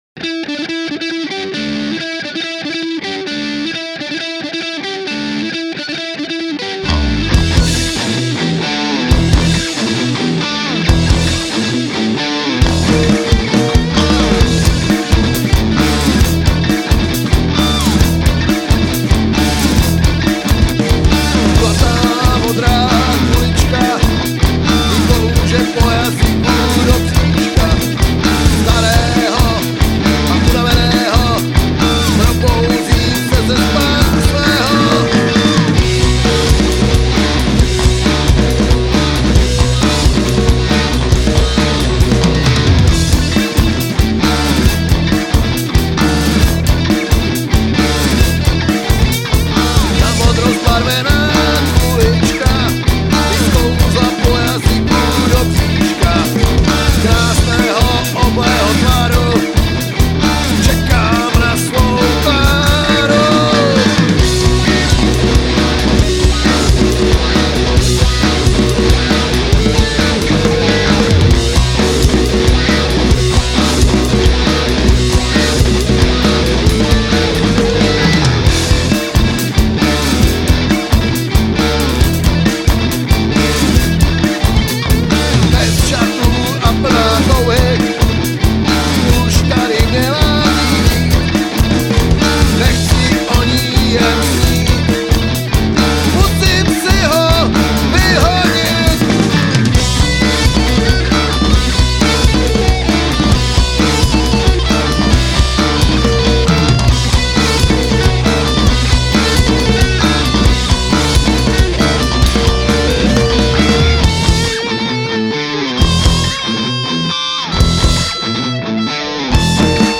Basa = Schecter + GK (linka + mikrofon)